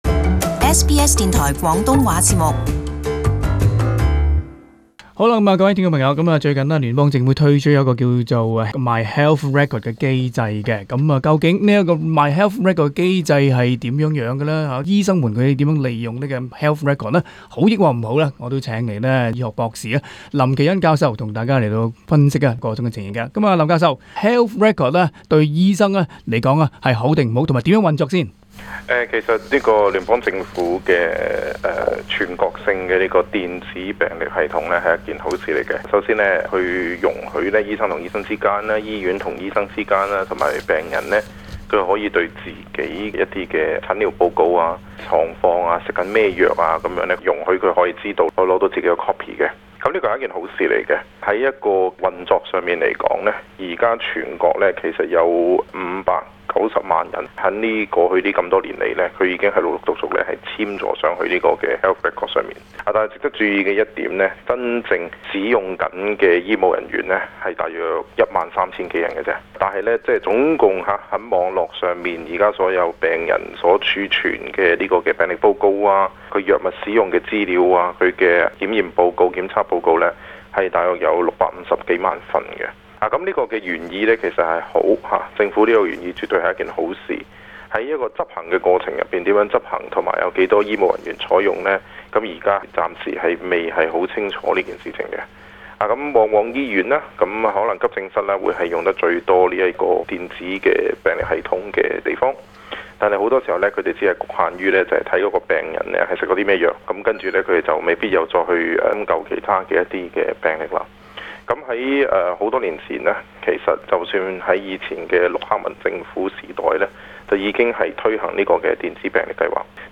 【社區專訪】從醫生角度審視個人電子病歷現存的優點與缺憾